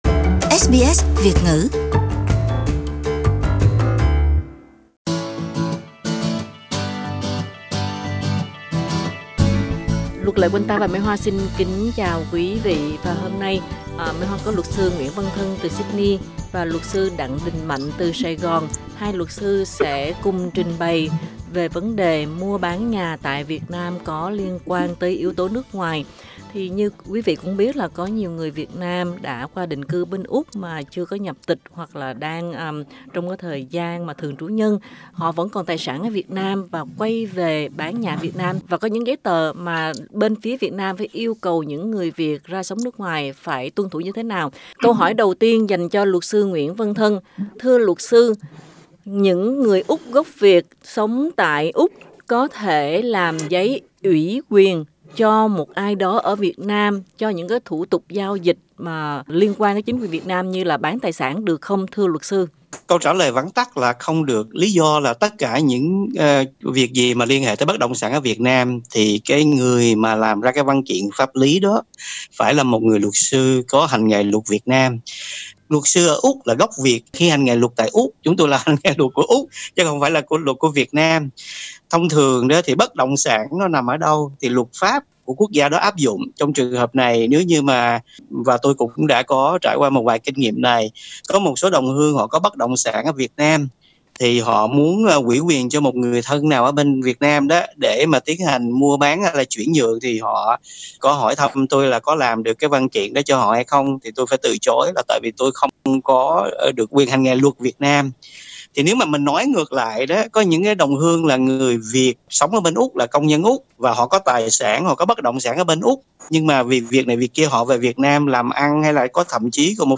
Hội luận